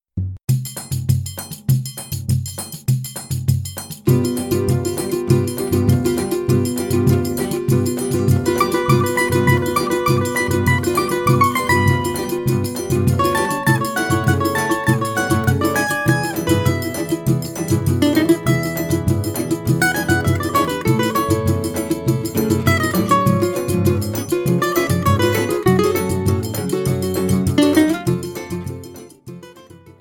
cavaquinho
Choro ensemble